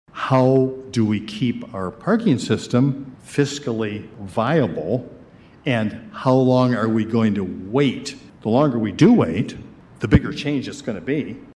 Mayor Dave Anderson says the parking system is already running in the red, and road construction will be going on for the next 4 to 5 years.